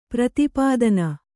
♪ prati pādana